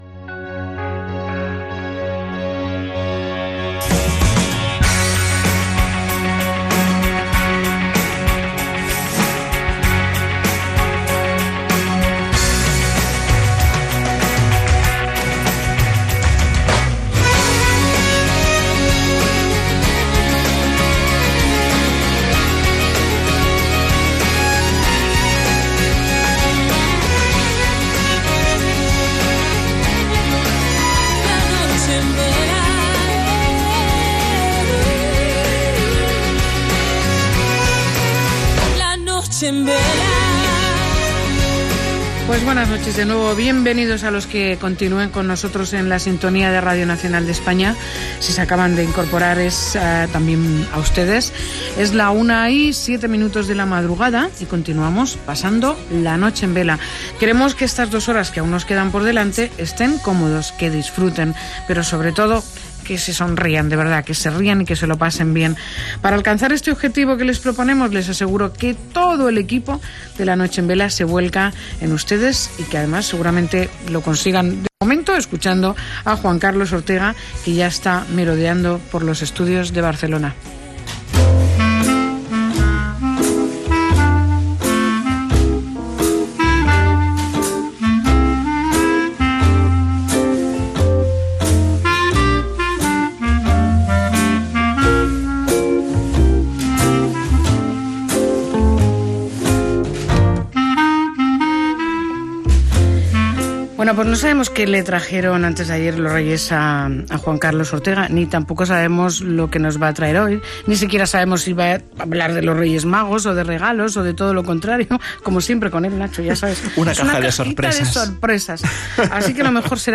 Sintonia cantada
Entreteniment